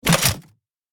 Rocket Launcher Loading 2 Sound Effect Download | Gfx Sounds
Rocket-launcher-loading-2.mp3